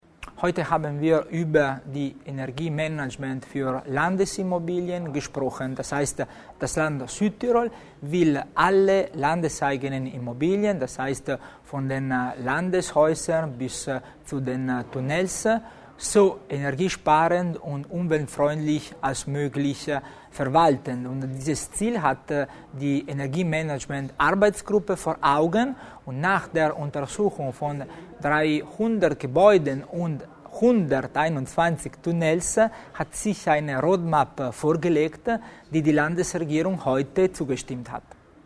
Landeshauptmannstellvertreter Tommasini zu den Maßnahmen in Sachen Energieverbrauch